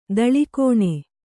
♪ daḷi kōṇe